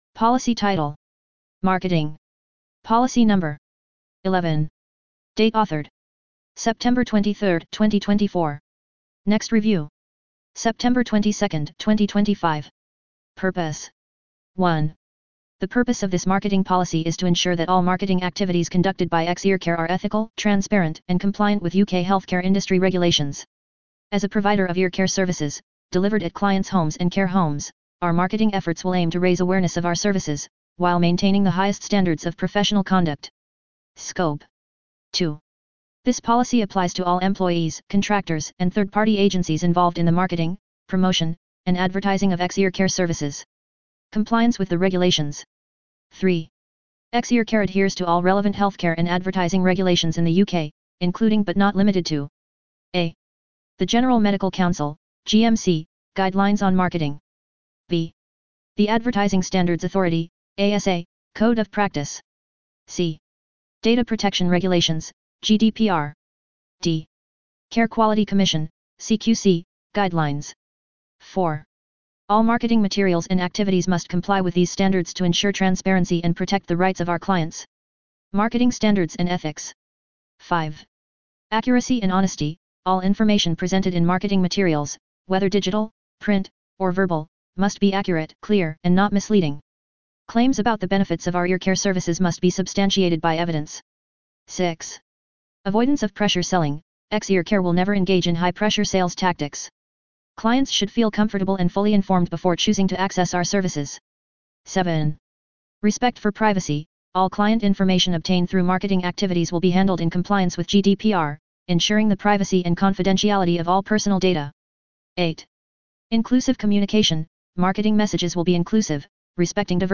Narration of Marketing Policy